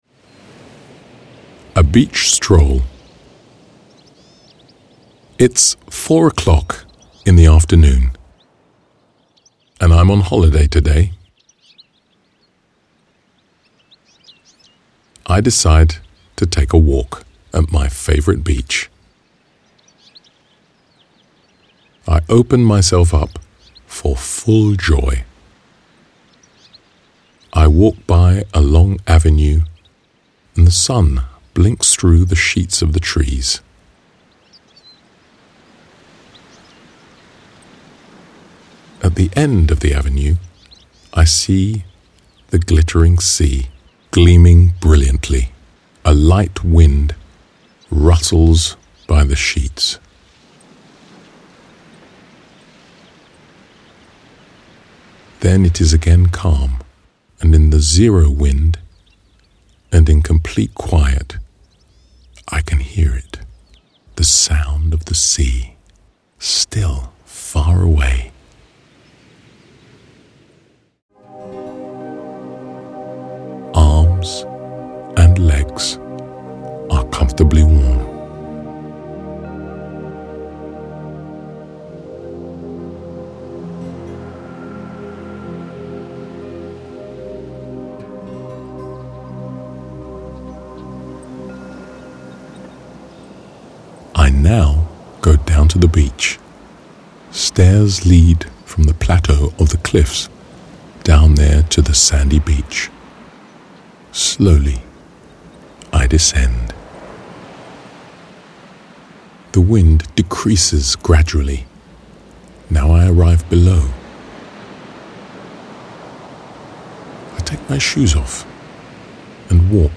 Buy audiobook:
The calm, clear voice and the spatial, gentle sounds will help you to quickly immerse yourself in a state of deep relaxation.